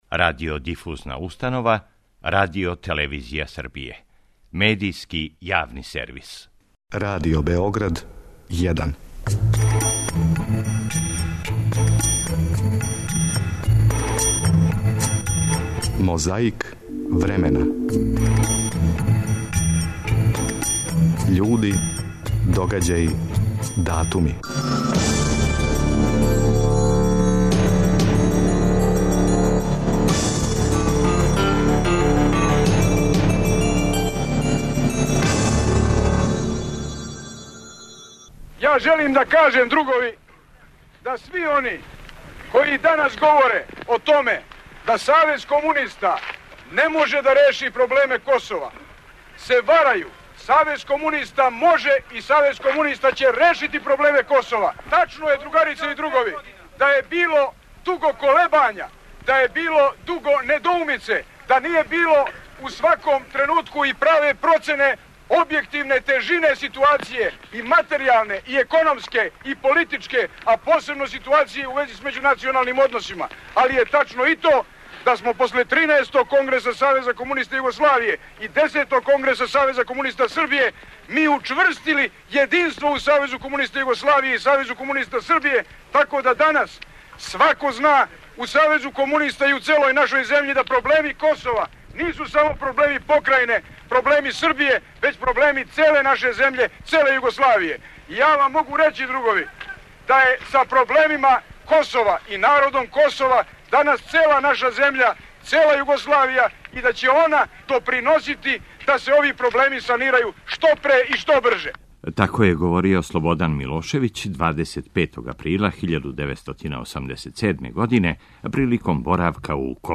Овонедељну борбу против пилећег памћења 'отвара' Слободан Милошевић. Чућете шта је говорио, када је, 20. априла 1987. (беше понедељак) био у Косову Пољу, у дворишту основне школе.